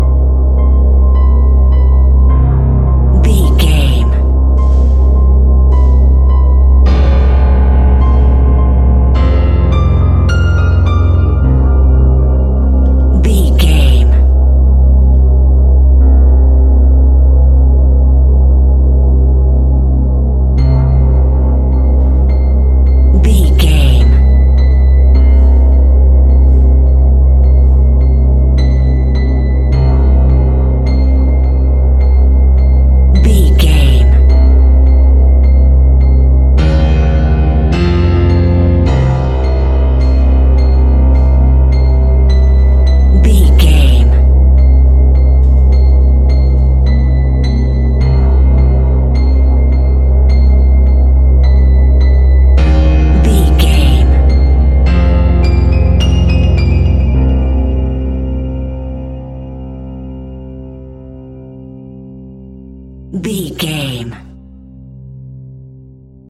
In-crescendo
Thriller
Aeolian/Minor
ominous
eerie
horror music
Horror Pads
horror piano
Horror Synths